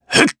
Ezekiel-Vox_Casting1_jp.wav